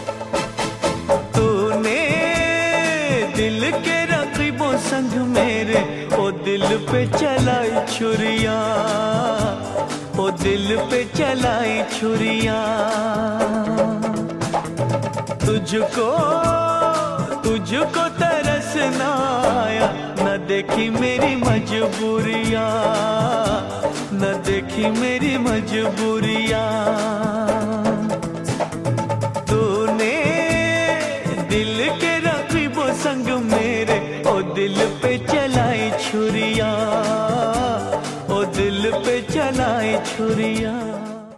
Ringtone File
Bollywood Songs